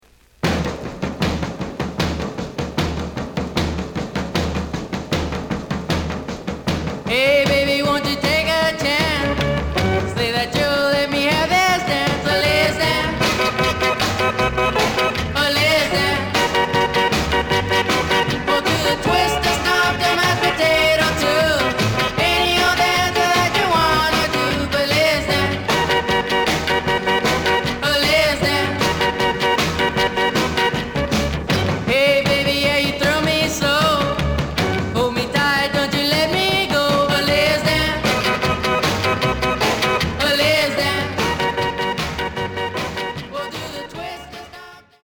The audio sample is recorded from the actual item.
●Genre: Rhythm And Blues / Rock 'n' Roll
●Record Grading: VG~VG+ (傷はあるが、プレイはおおむね良好。Plays good.)